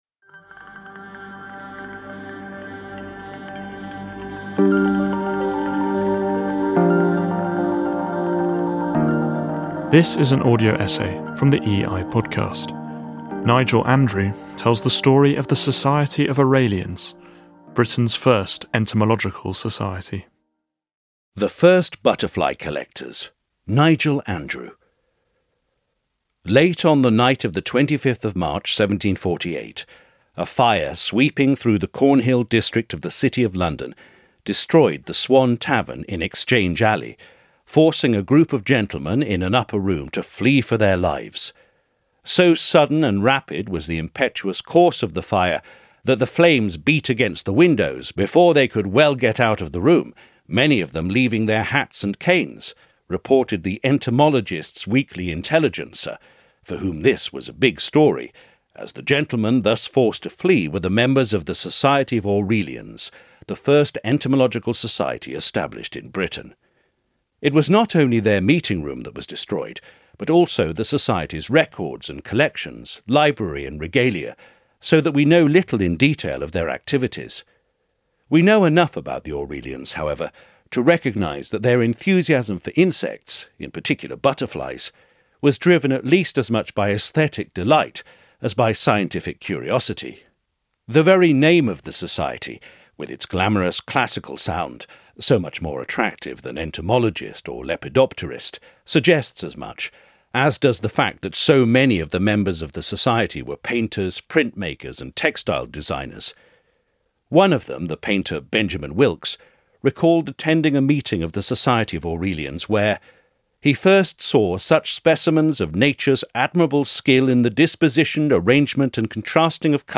audio essay